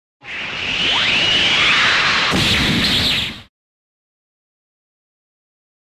Heavy Punch (Anime) Sound
Heavy Punch (Anime)